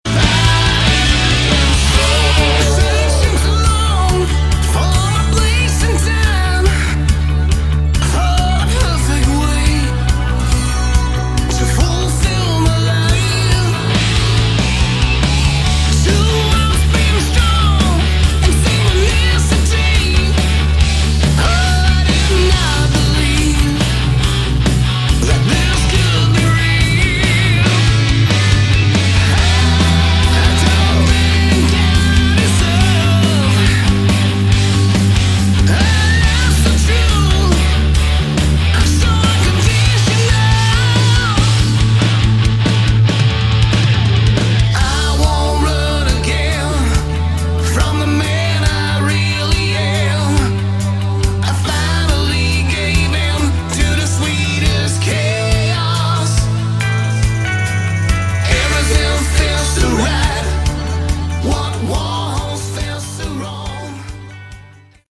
Category: Hard Rock
Vox
Guitars, Bass, Keyboards
Drums